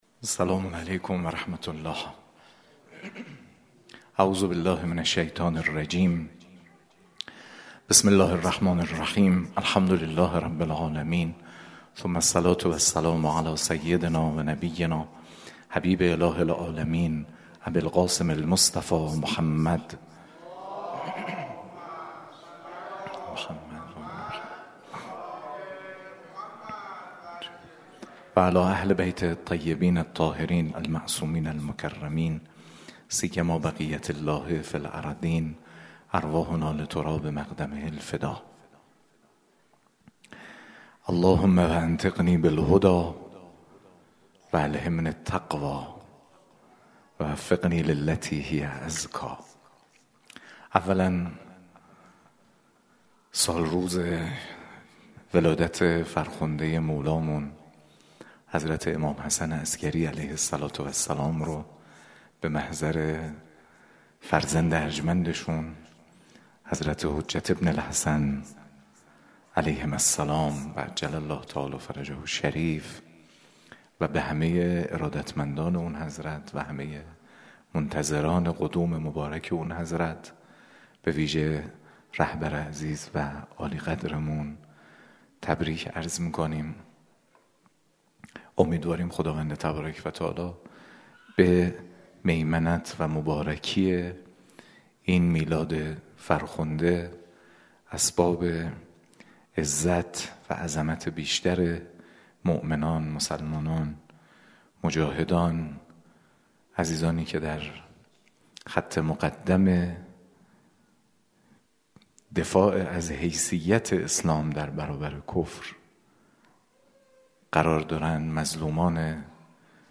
به گزارش ایکنا، جلسه شرح صحیفه سجادیه با سخنان حجت‌الاسلام والمسلمین محمدجواد حاج علی‌اکبری؛ رئیس شورای سیاست‌گذاری ائمه جمعه، طبق روال هر هفته، شامگاه دوشنبه اول آبان در مجموعه فرهنگی سرچشمه برگزار شد که گزیده مباحث آن را در ادامه می‌خوانید؛